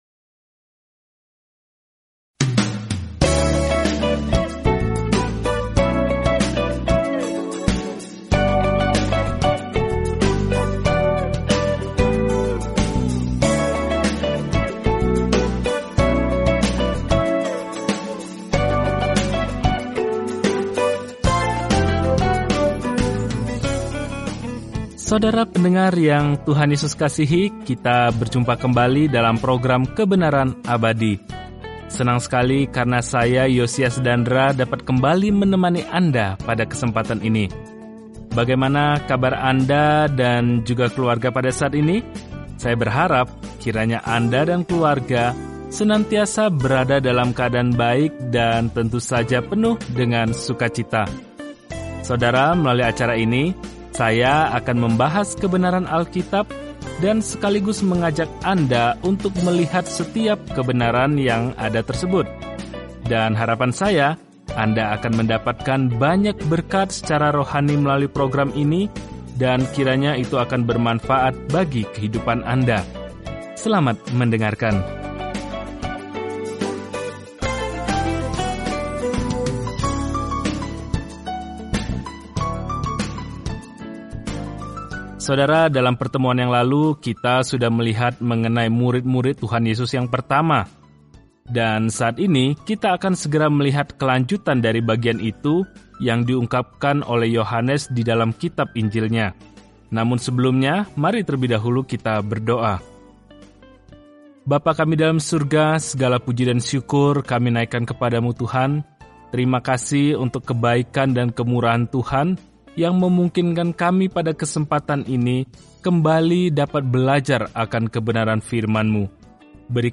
Firman Tuhan, Alkitab Yohanes 1:51 Yohanes 2:1-11 Hari 3 Mulai Rencana ini Hari 5 Tentang Rencana ini Kabar baik yang dijelaskan Yohanes unik dibandingkan Injil lainnya dan berfokus pada mengapa kita hendaknya percaya kepada Yesus Kristus dan bagaimana memiliki kehidupan dalam nama ini. Telusuri Yohanes setiap hari sambil mendengarkan pelajaran audio dan membaca ayat-ayat tertentu dari firman Tuhan.